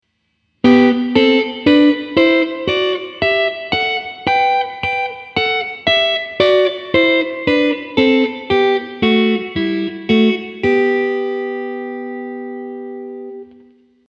6度音程のダブルストップ1弦、3弦